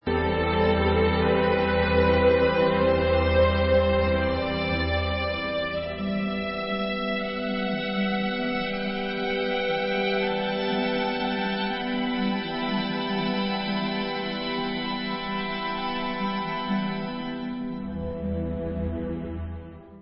ve studiu Abbey Road